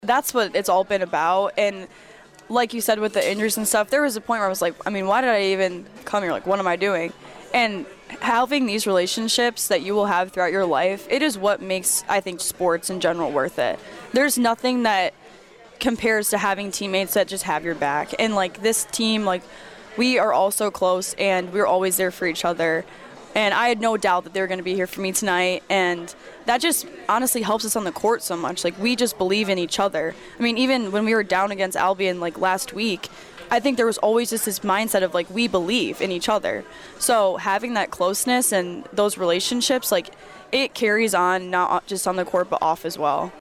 guest on Monday’s AC All-Access Show at Fricker’s in Adrian…aired on 96.5fm The Cave.